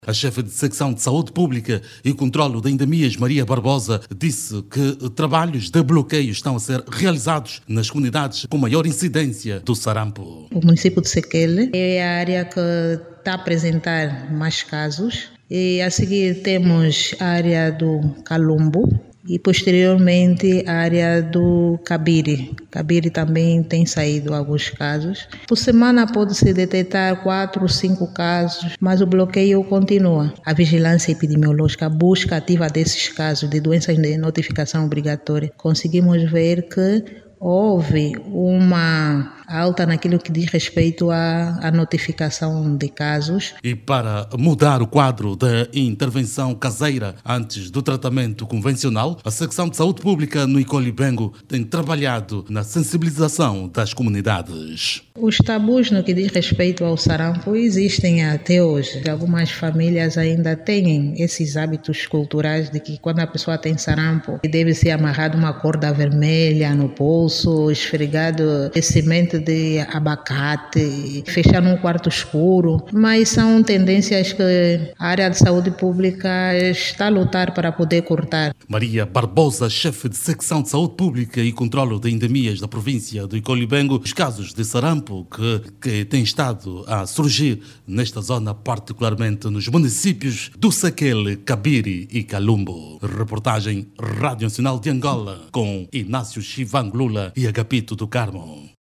A província de Icolo e Bengo, está a registar um aumento preocupante de casos de sarampo, com uma média de quatro a cinco diagnósticos por dia. As autoridades sanitárias mostram-se alarmadas com a situação, principalmente nos municípios do Sequele, Cabiri e Calumbo, que são atualmente os mais afectados. Saiba mais dados no áudio abaixo com o repórter